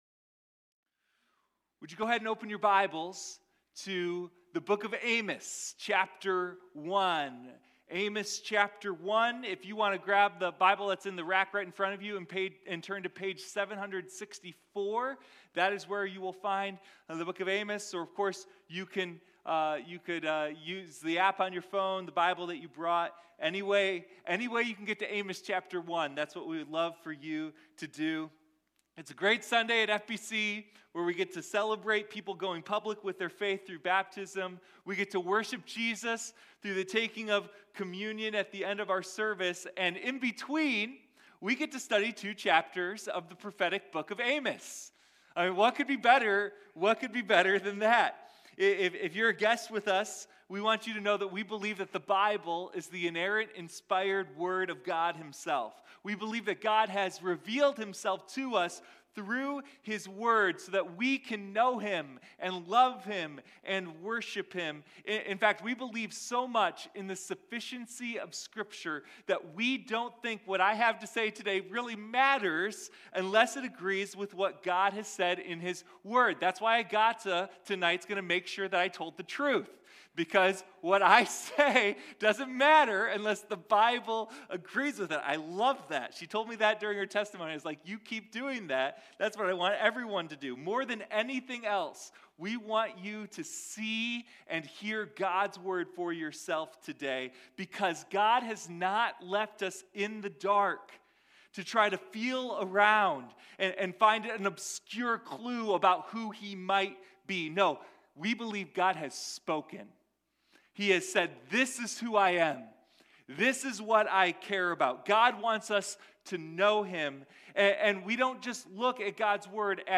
Sunday Morning Amos: The Roar of Justice